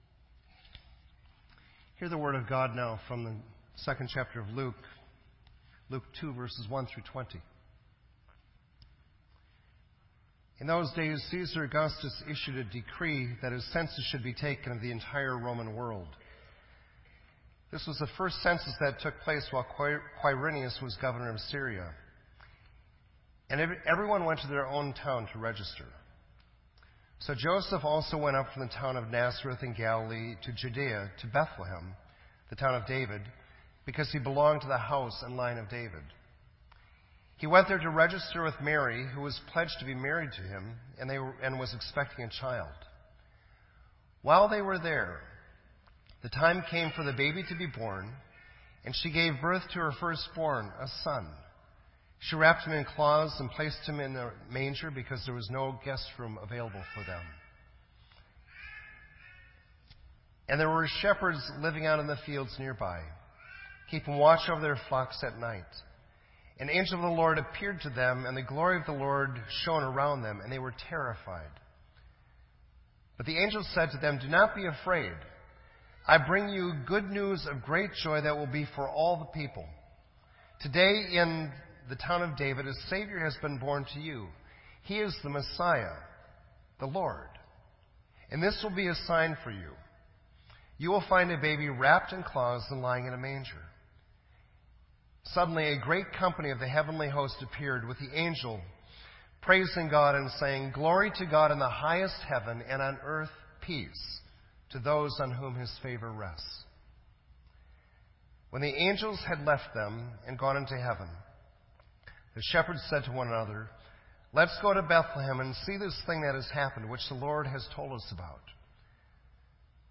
Reflection
This entry was posted in Sermon Audio on December 27